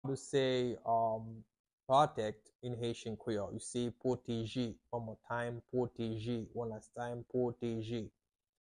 How to say "Protect" in Haitian Creole - "Pwoteje" pronunciation by a native Haitian tutor
“Pwoteje” Pronunciation in Haitian Creole by a native Haitian can be heard in the audio here or in the video below:
How-to-say-Protect-in-Haitian-Creole-Pwoteje-pronunciation-by-a-native-Haitian-tutor.mp3